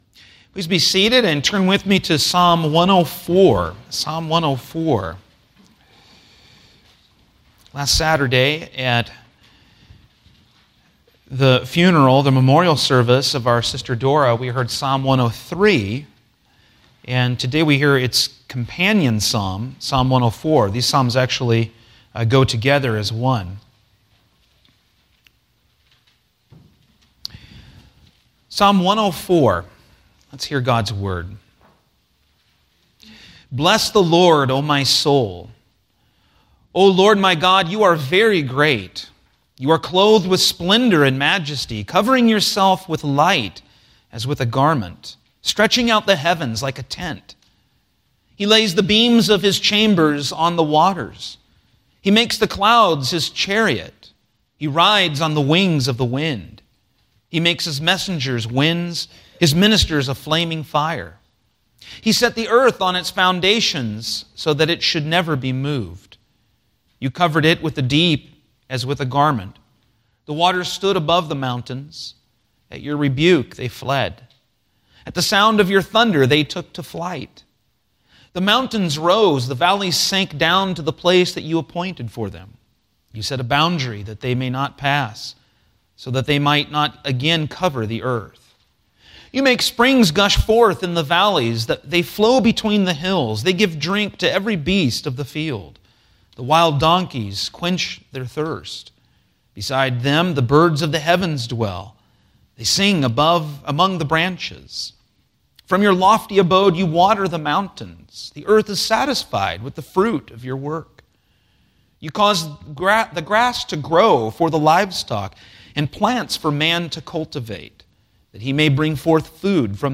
Thanksgiving — Sermons — Christ United Reformed Church